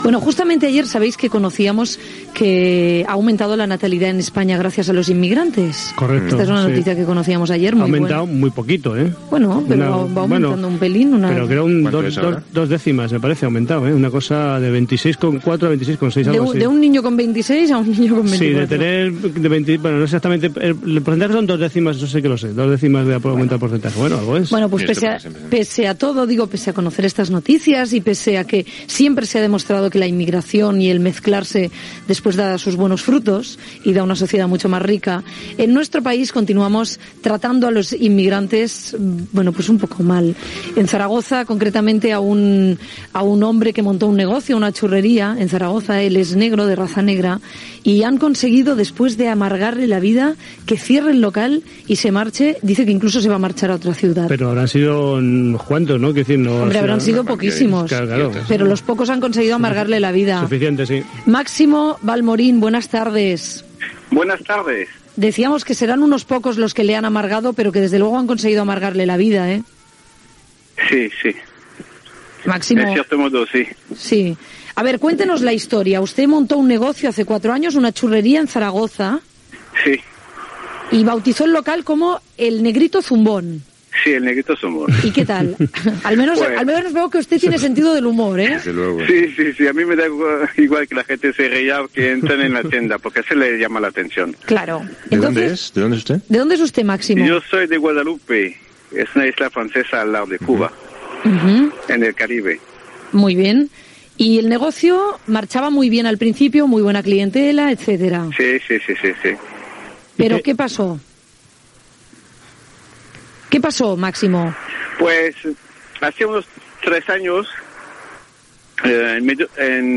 Comentari sobre l'increment de la natalitat gràcies als inmigrants. Fragment d'una entrevista a una persona de raça negre que va montar una xurreria a Saragossa.
Entreteniment
FM